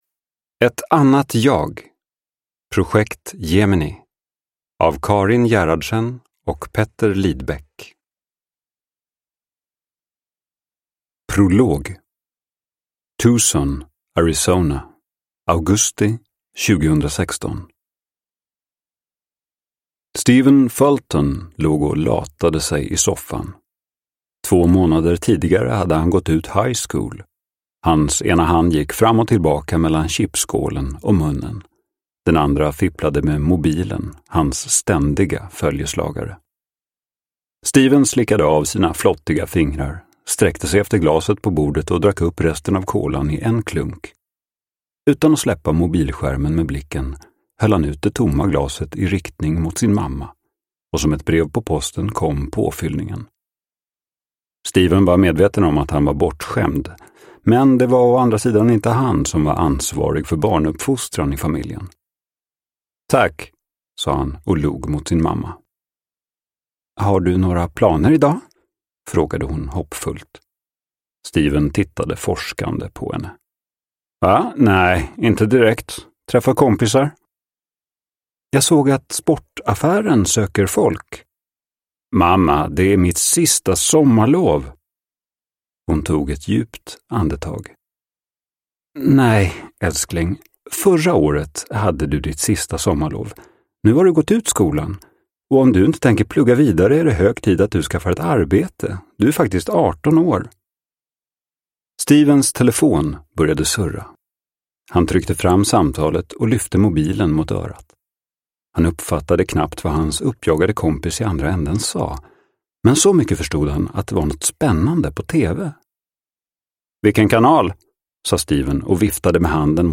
Ett annat jag : Projekt Gemini – Ljudbok – Laddas ner